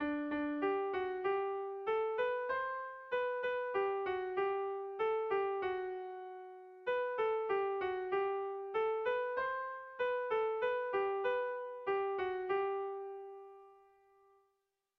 Haurrentzakoa
ABDE